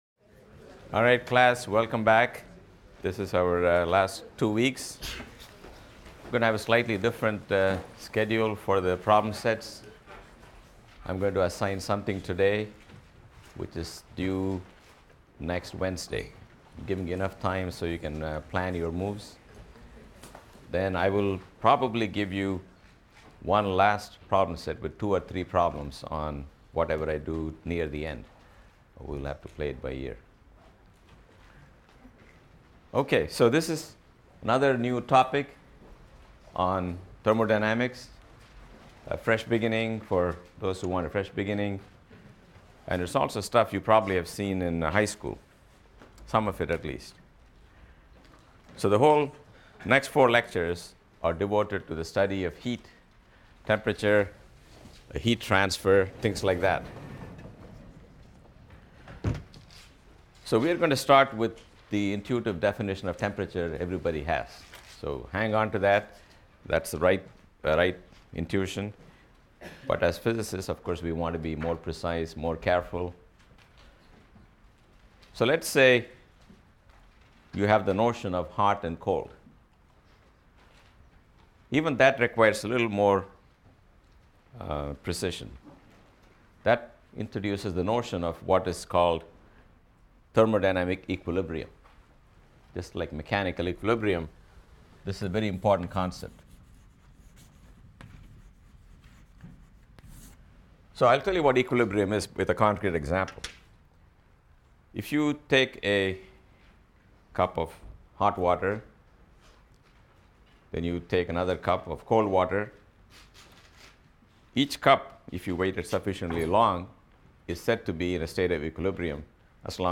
PHYS 200 - Lecture 21 - Thermodynamics | Open Yale Courses